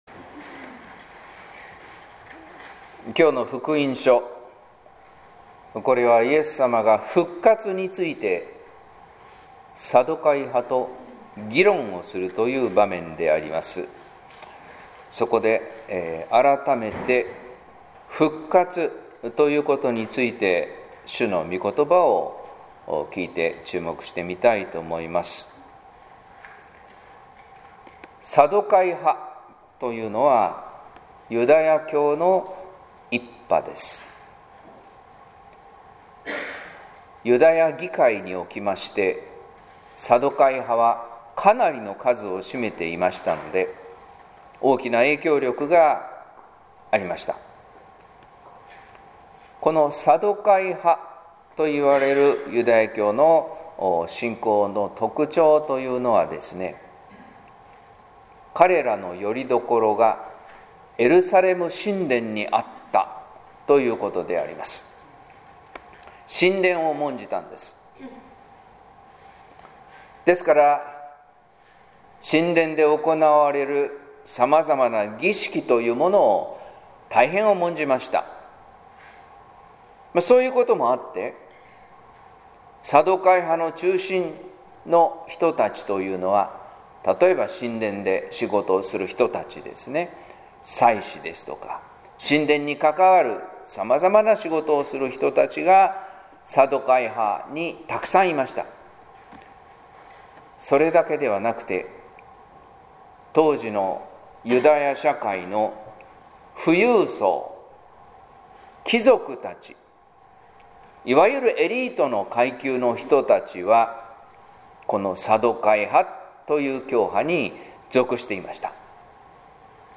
説教「生きるを委ねる」（音声版）